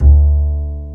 Index of /90_sSampleCDs/Roland - Rhythm Section/BS _Jazz Bass/BS _Ac.Fretless